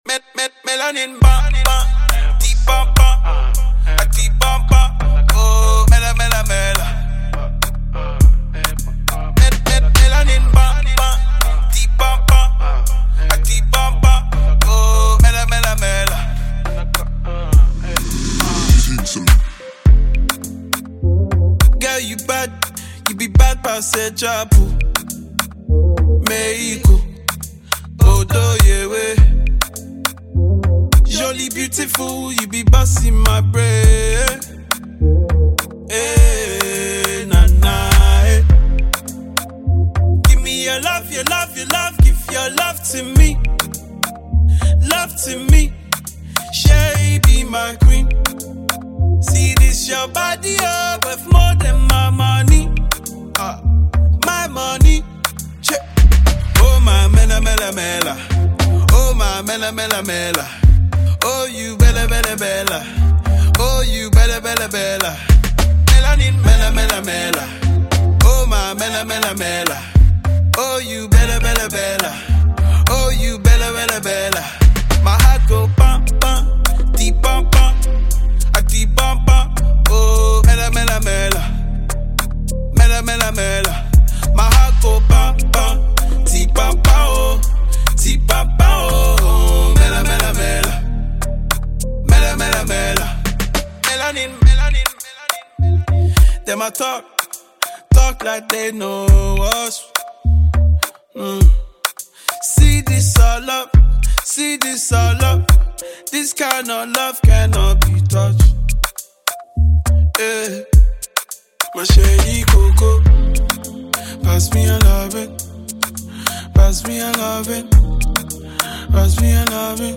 Ivorian-British singer